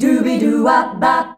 DUBIDUWA C.wav